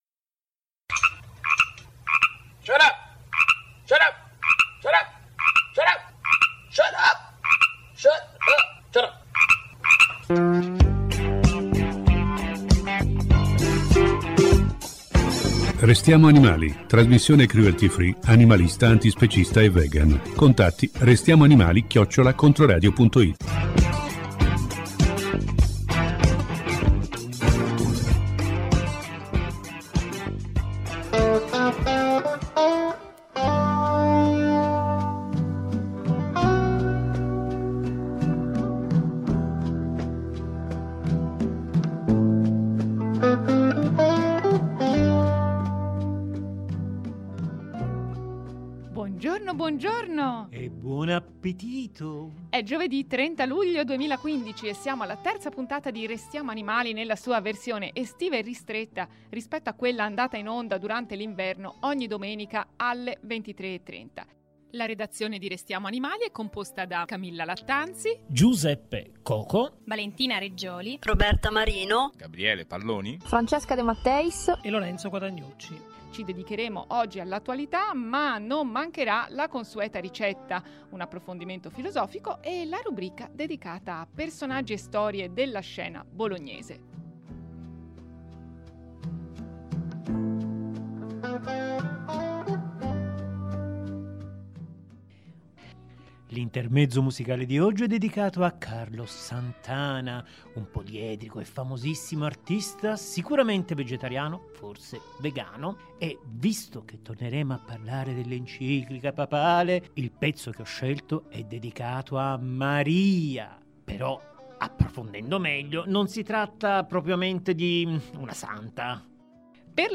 Torniamo sulla vicenda che riguarda l’isola-carcere di Gorgona con un’intervista